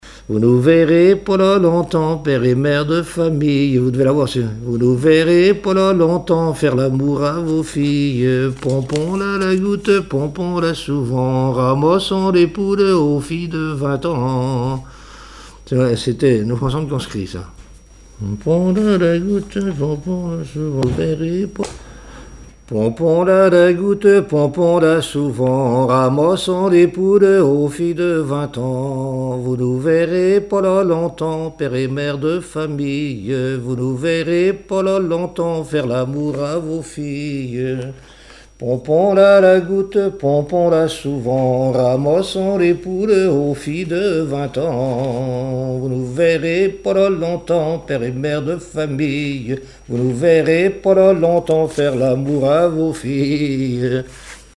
Chants brefs - Conscription
Fonction d'après l'analyste gestuel : à marcher
Témoignages et chansons
Pièce musicale inédite